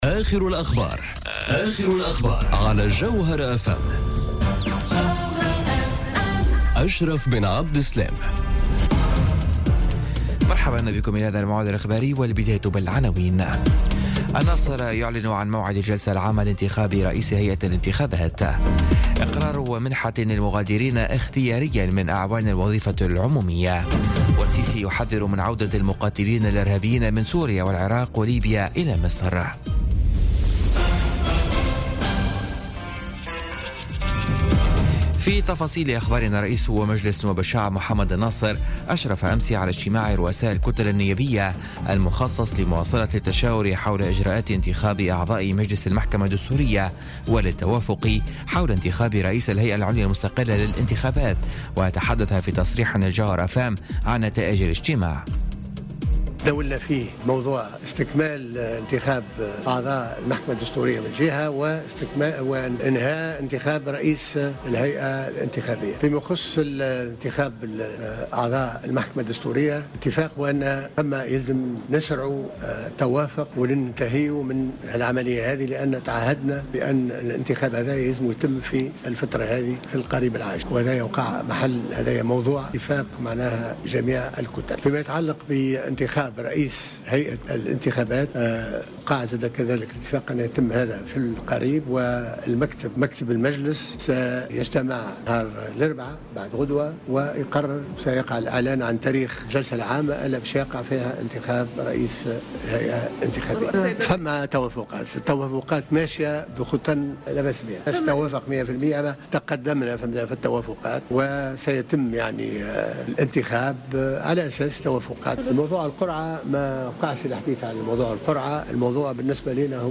نشرة أخبار منتصف الليل ليوم الثلاثاء 24 أكتوبر 2017